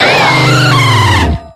infinitefusion-e18/Audio/SE/Cries/DIALGA.ogg at a50151c4af7b086115dea36392b4bdbb65a07231